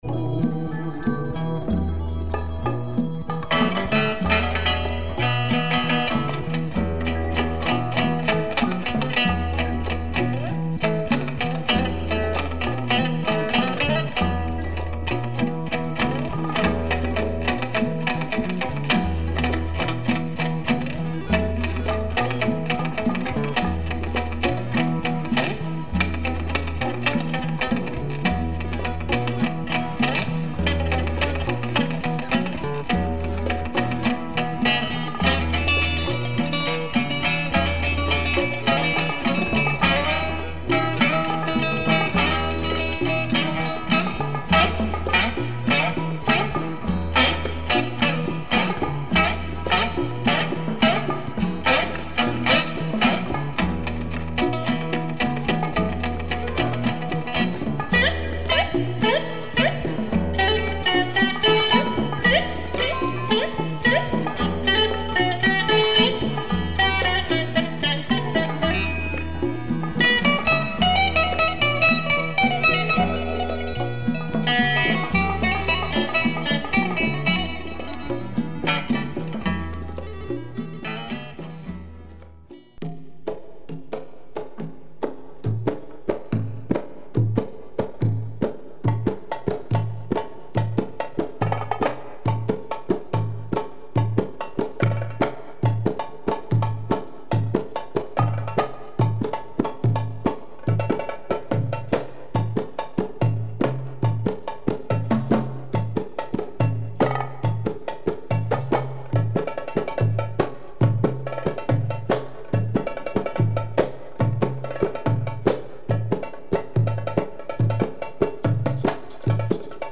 Belly dance music